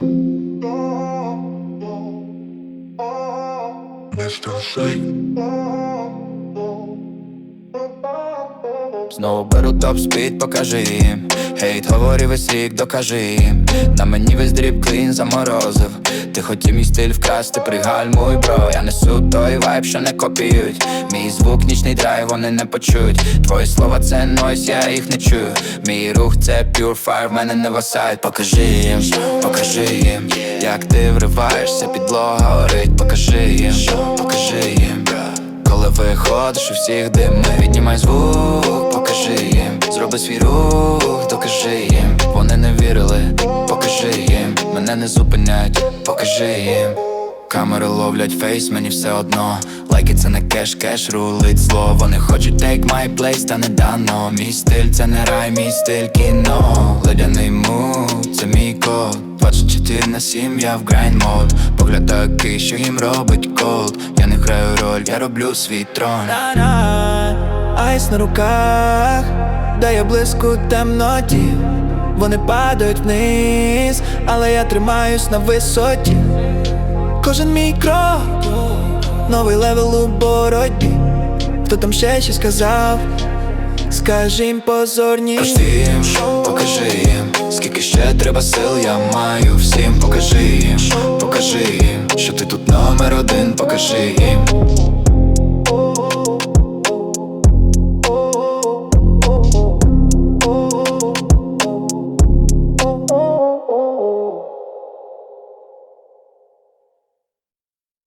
• Жанр:Реп